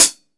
Index of /90_sSampleCDs/AKAI S6000 CD-ROM - Volume 3/Hi-Hat/STUDIO_HI_HAT